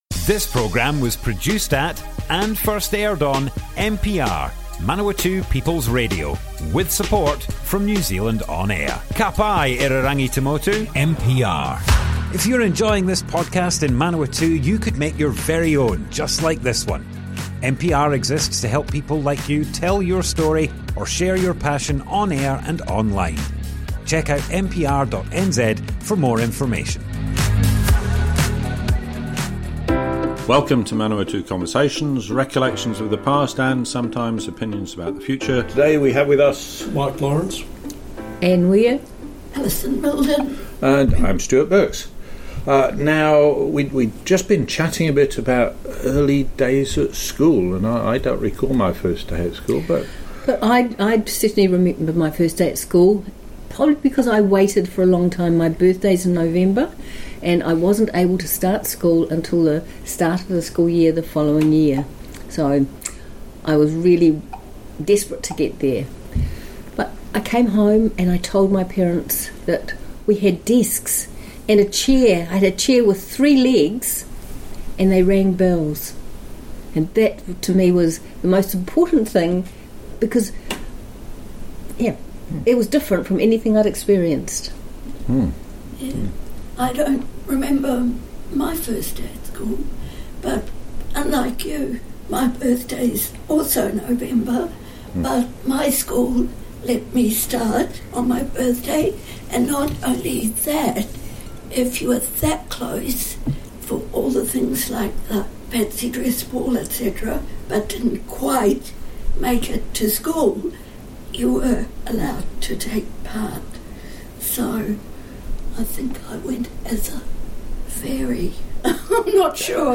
Group discussion Part 2, early school days - Manawatu Conversations